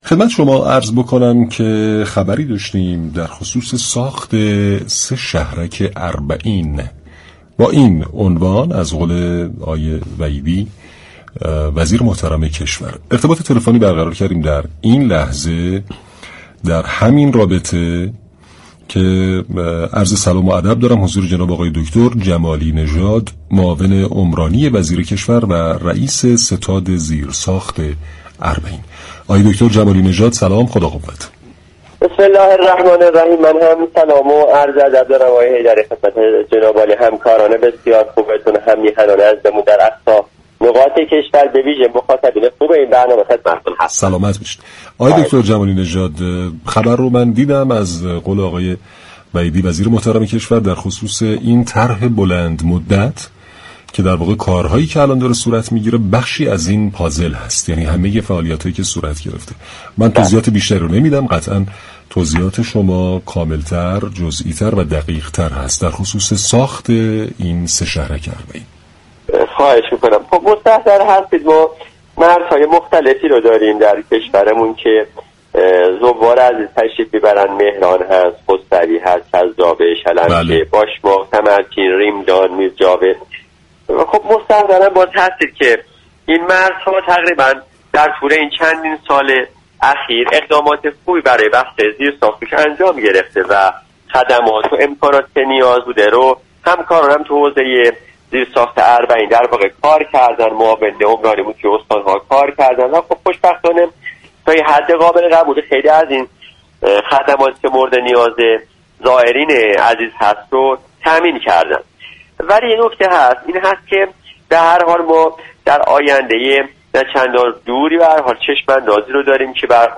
به گزارش پایگاه اطلاع رسانی رادیو تهران؛ مهدی جمالی‌نژاد معاون عمران و توسعه امور شهری و روستایی وزیر كشور و رئیس زیرساخت ستاد اربعین در گفتگو با برنامه «سعادت آباد» درباره ساخت چهار شهرك اربعینی در مرزهای شلمچه، چذابه و مهران و خسروی گفت: ما مرزهای مختلفی با عراق داریم كه می‌توان به شلمچه، مهران، خسروی، ریمدان، باشماق، تمرچین و چذابه اشاره كرد كه از طریق آنها زائران به عراق می‌روند.